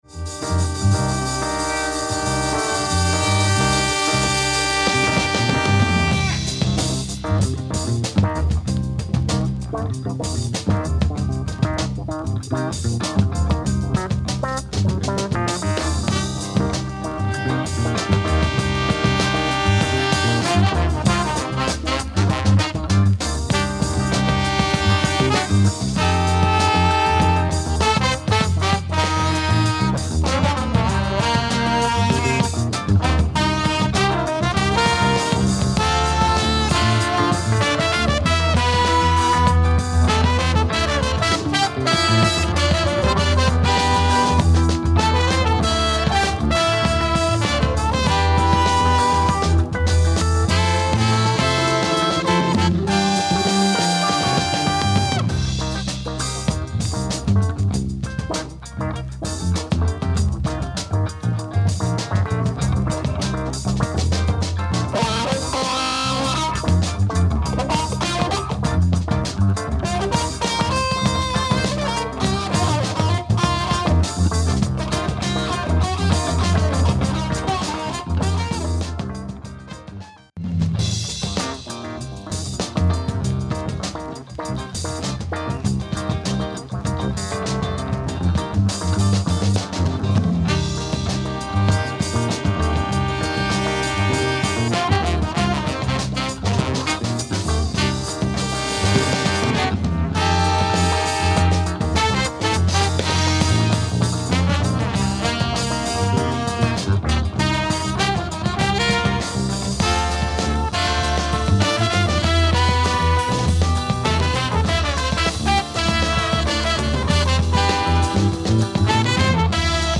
crazy horns and keyboards